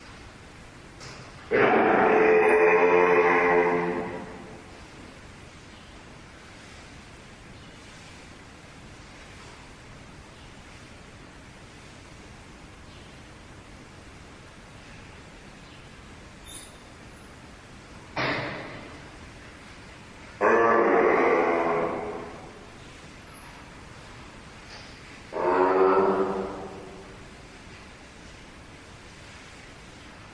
担当者が姿を見せると鳴かないので、ICレコーダーを使って録音してみることにしました。
しかも「モ〜」だけではなく、「ア〜」とか「ウ〜」とか「アッ」みたいな短い声もありました（あまり正確な表現ではありませんが）。
録音した鳴き声
（mp3形式、約30秒。最初と最後に鳴き声が聞こえます）
giraffe_bleat.mp3